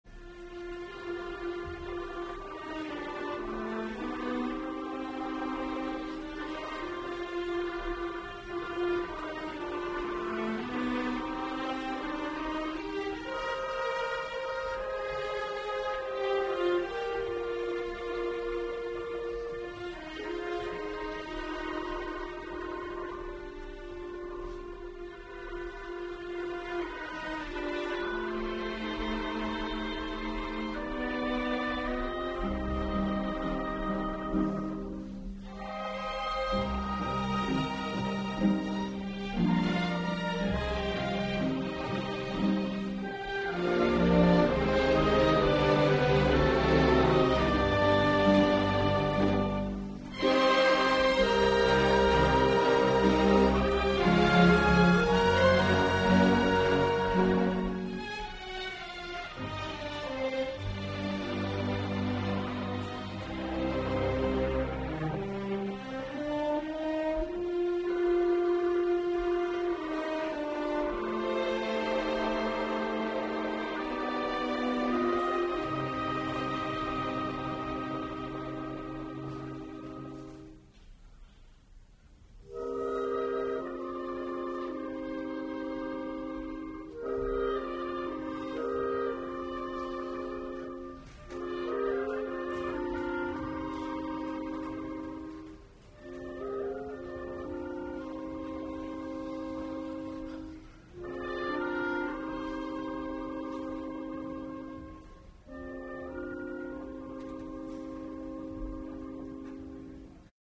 Preludio
mascagni - i rantzau - preludio (atto II).mp3